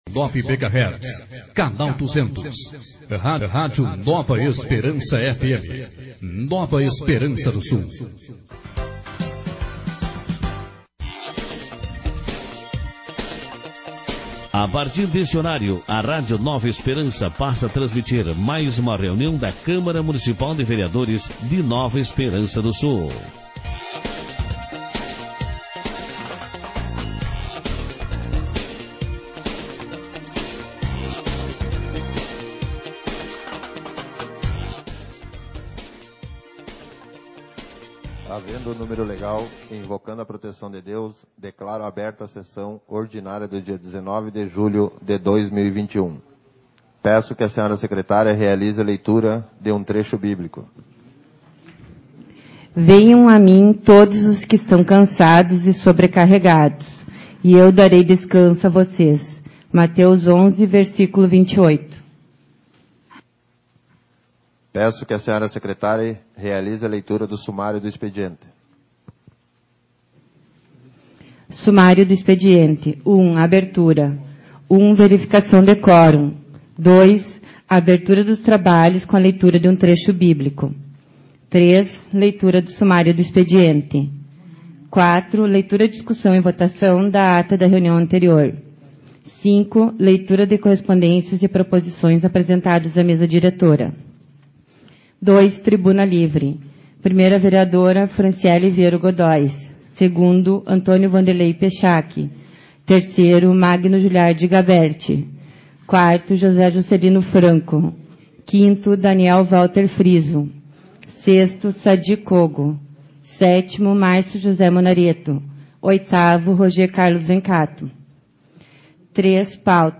audios sessão